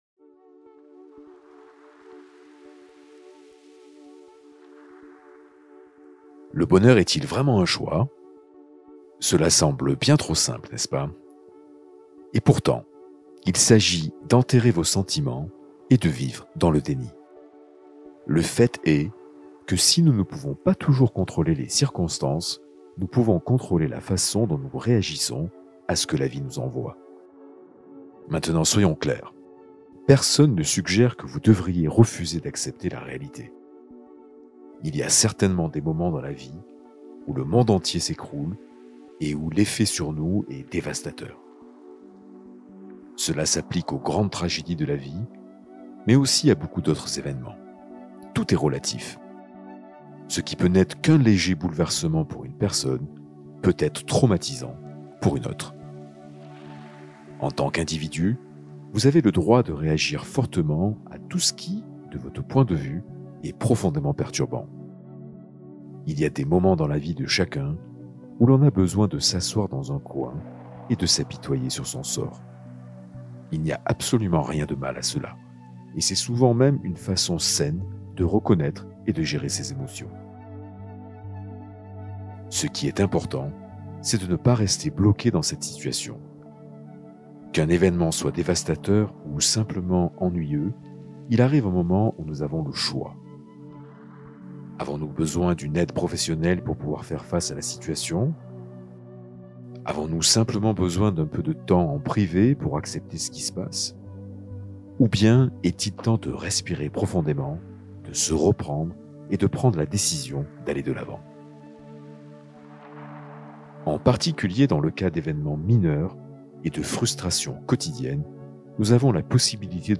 Rencontre ton Ange Gardien avec cette méditation guidée